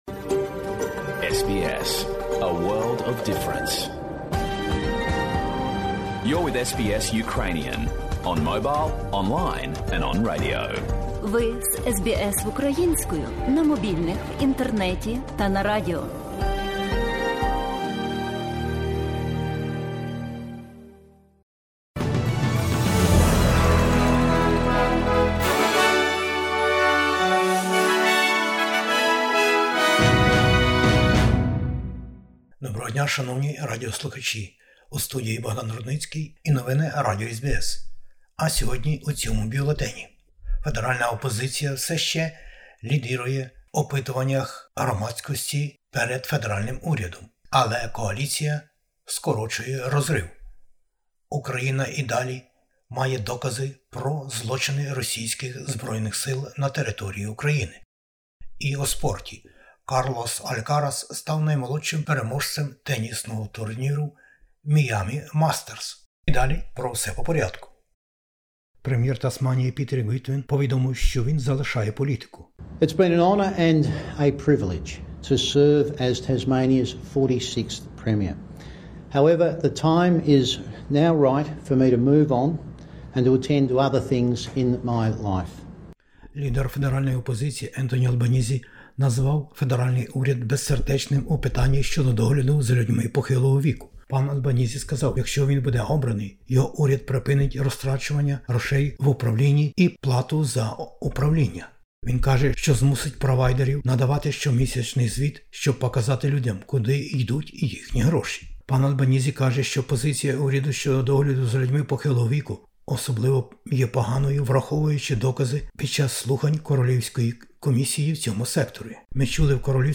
Бюлетень новин SBS українською. Опитування громадської думки і передвиборчі баталії. Прем'єр Тасманії залишає політику. Президент України звернувся до п-і Меркель і п. Саркозі - жахіття війни у Бучі та Ірпені.